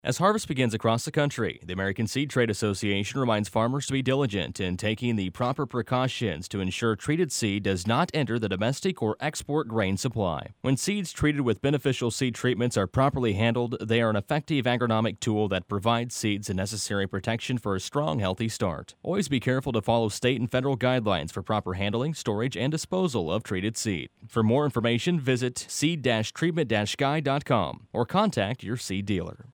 Public Service Announcement: Disposal of Treated Seed